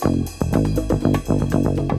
funksideways.mp3